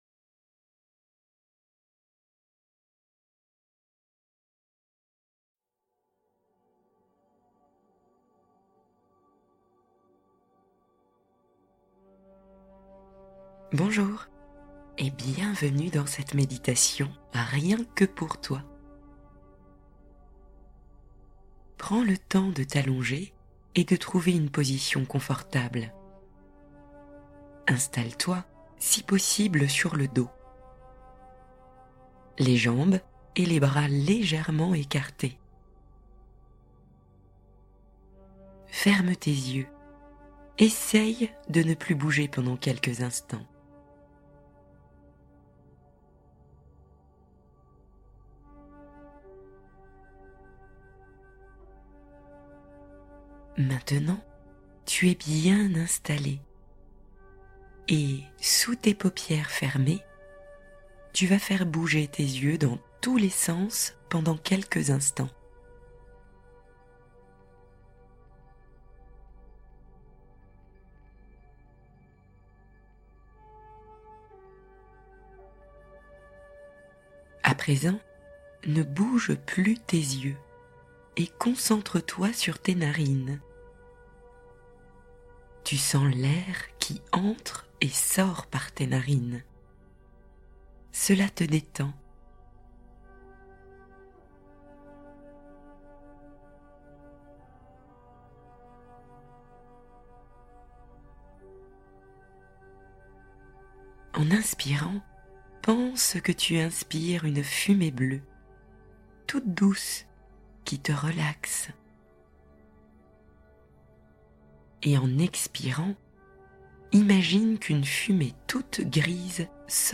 Rêves Merveilleux : Conte apaisant pour guider les enfants vers la nuit